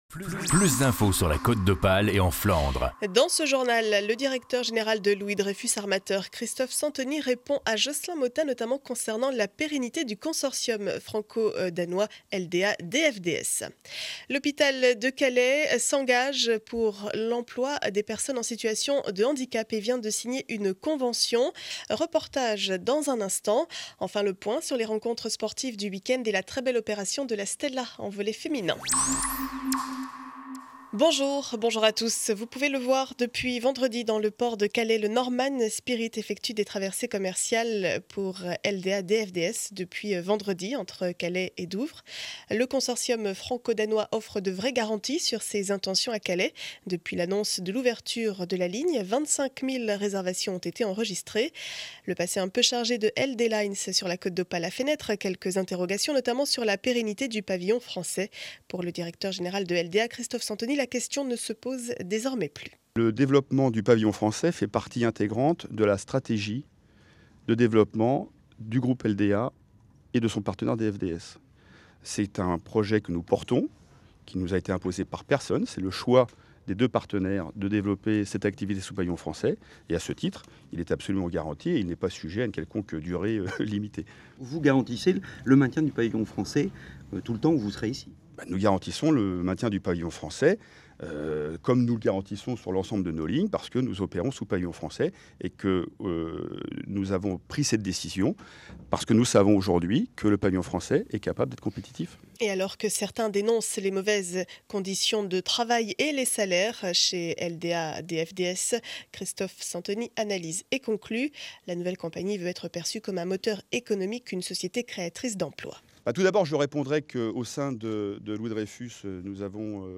Journal du lundi 20 février 2012 7 heures 30 édition du Calaisis.